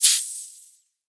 pressure_release02.wav